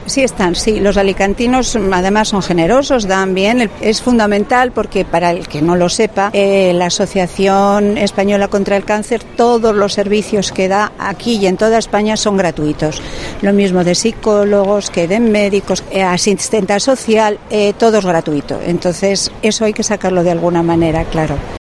Las voluntarias de la AECC explican lo importante que son las donaciones de los alicantinos